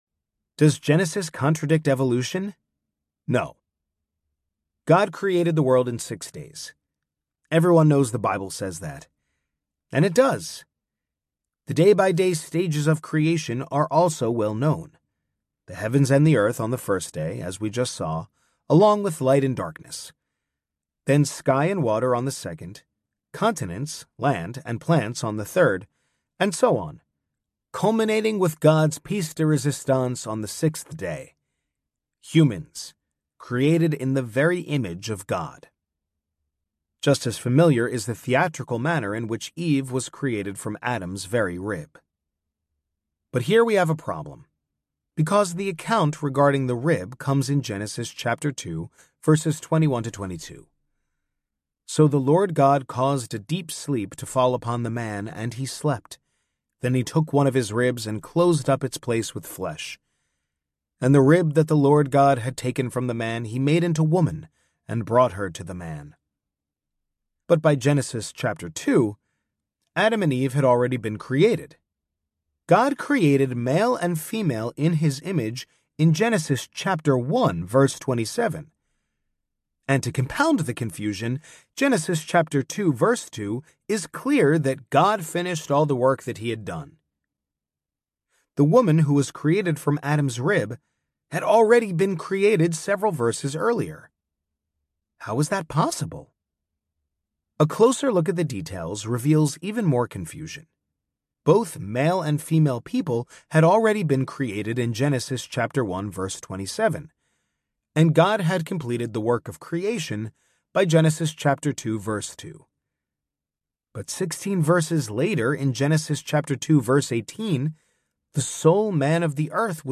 The Bible Doesn’t Say That Audiobook
9.9 Hrs. – Unabridged